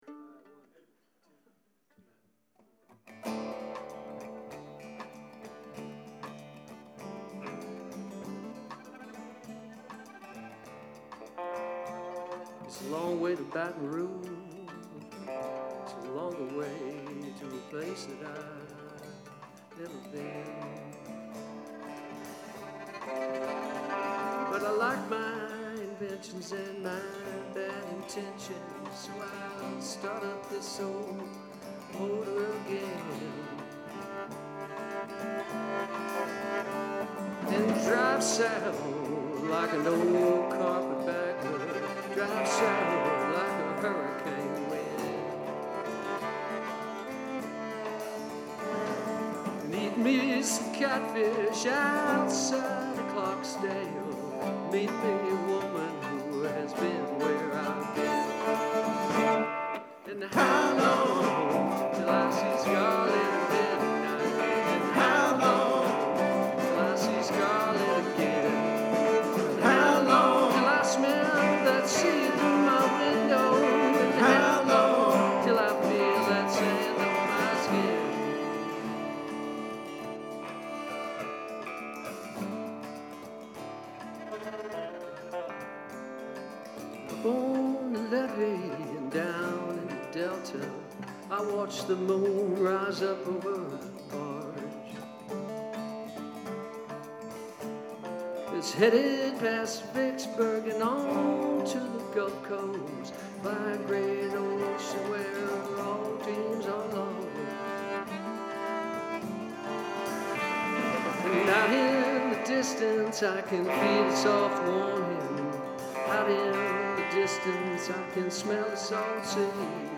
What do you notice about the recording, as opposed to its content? Two hour long sets with a rapt and appreciative audience.